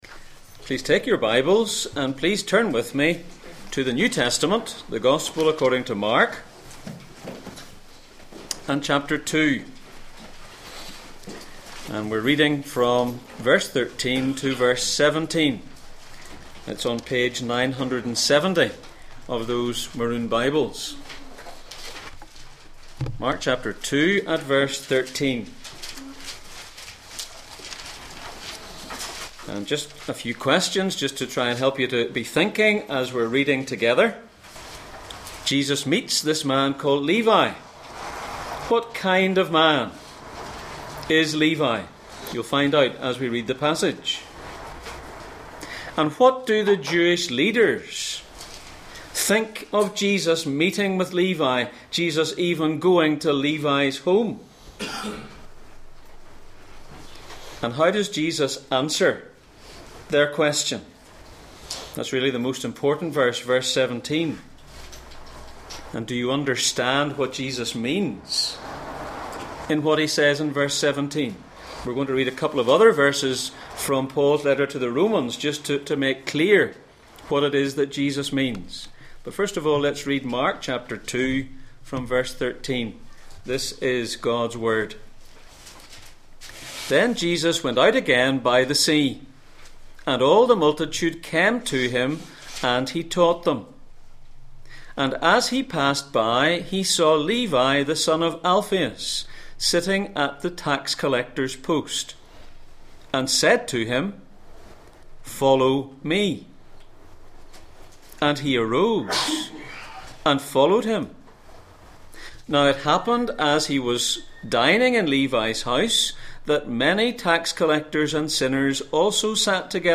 Mark 2:13-17 Service Type: Sunday Morning %todo_render% « Troubles are temporary